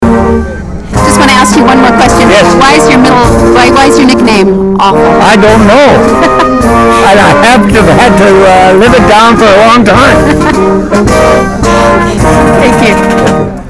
Interview took place during the Queen's Own Rifles of Canada Vancouver Island Branch 150th Anniversary Celebration.
Genre interviews oral histories